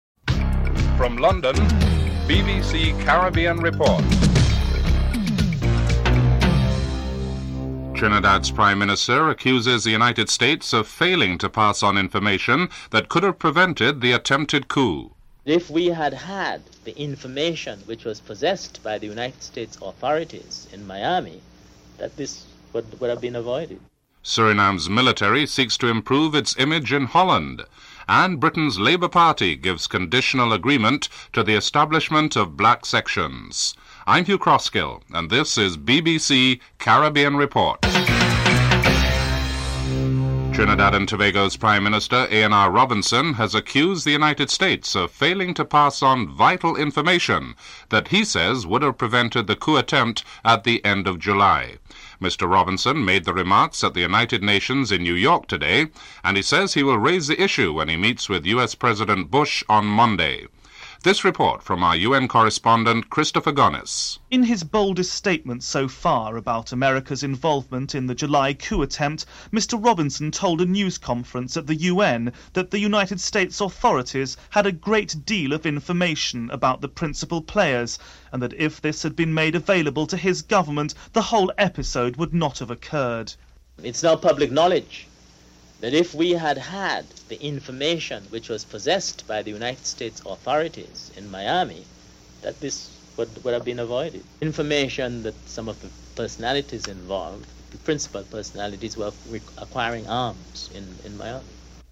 1. Headlines (00:00-00:39)
A Dutch journalist reports on the attempt by the former ambassador, Henk Herrenberg, to improve the image of the Surinamese military in Holland (02:52-05:53)